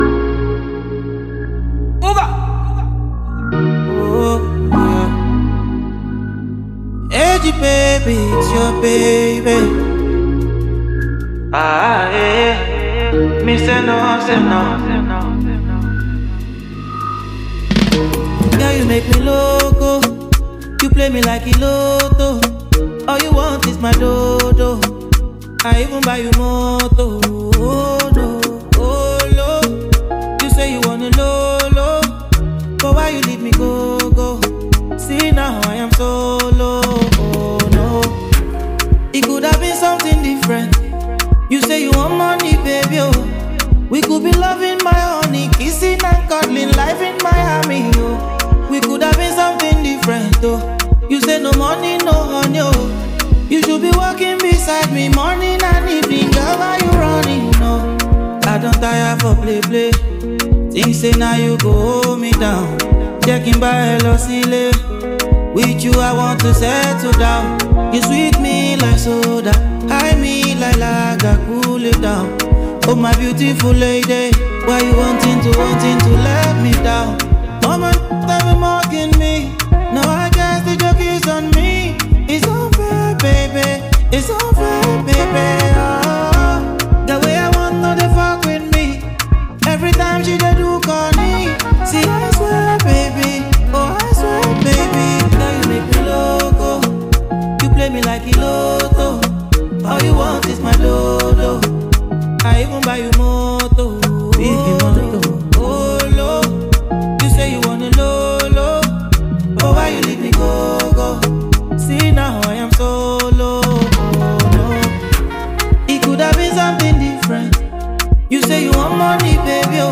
catchy single